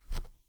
grassy-footstep3.wav